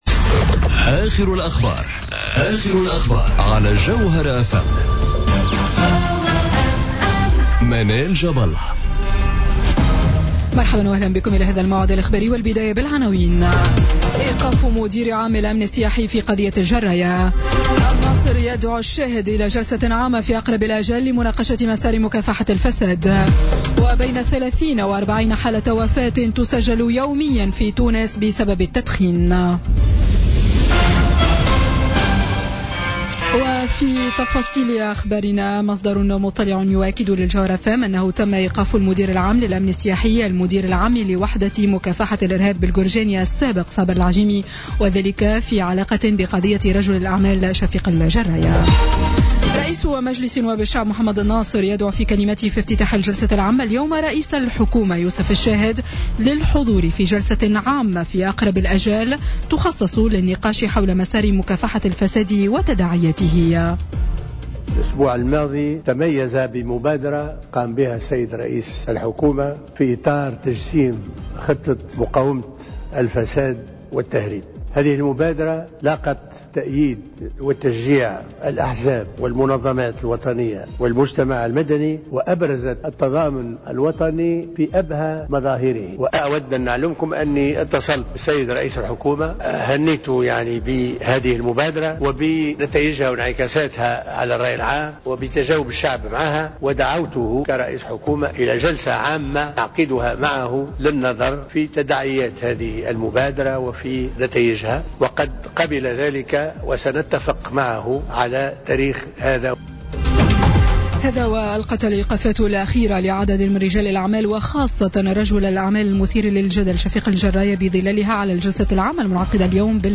نشرة أخبار السادسة مساء ليوم الثلاثاء 30 ماي 2017